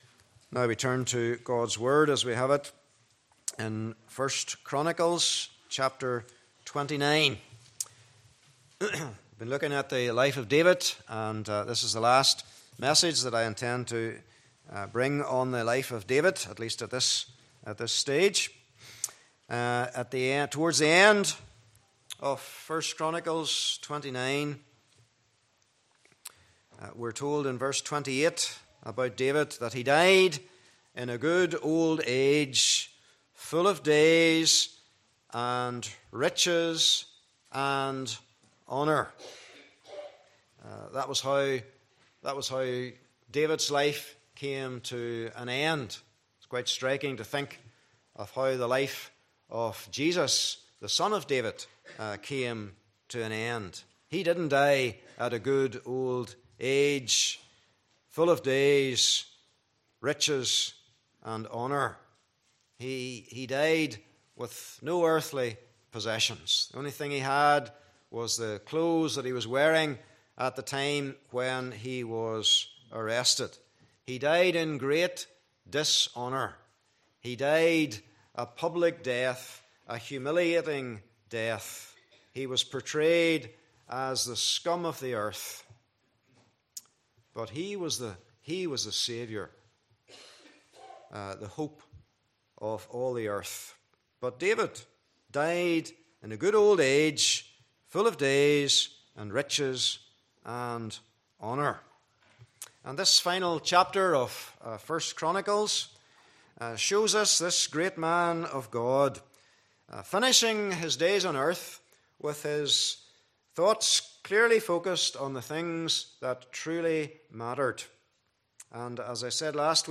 Passage: 1 Chronicles 29 :1 -30 Service Type: Morning Service